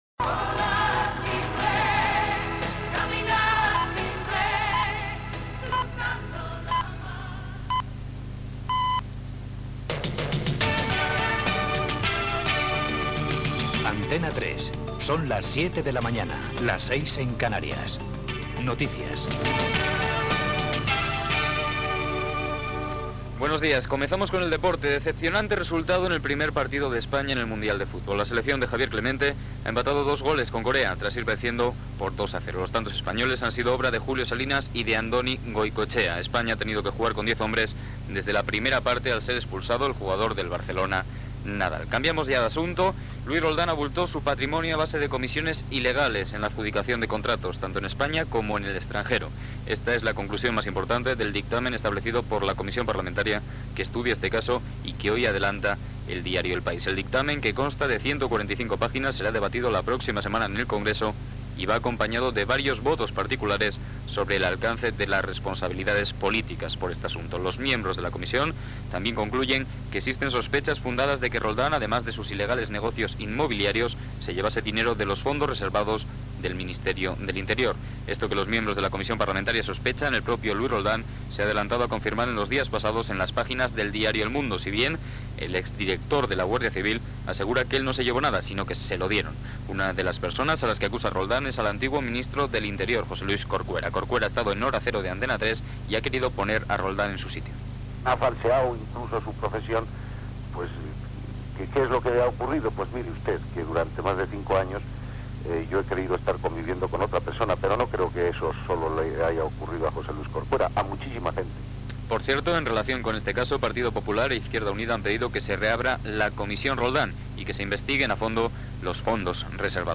Informatiu
Fragment de la darrera emissió d'Antena 3 Radio del 18 de juny de 1994.